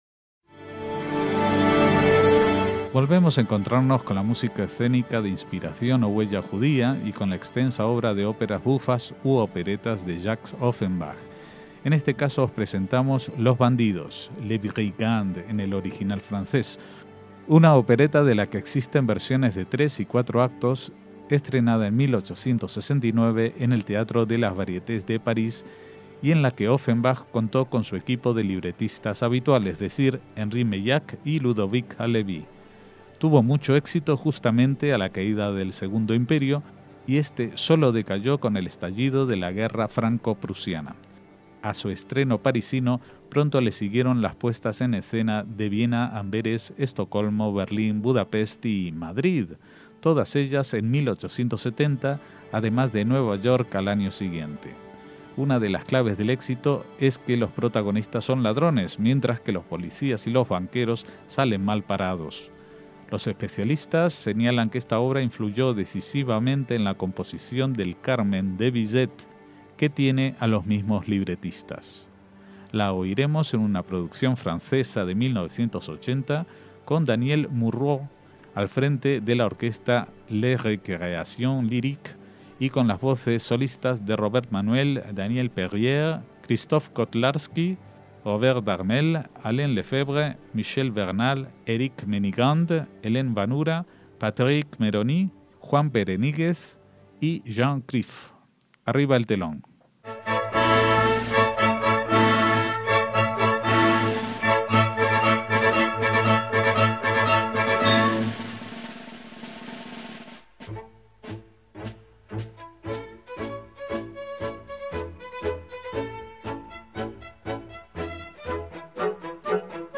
ÓPERA JUDAICA
ópera bufa en tres actos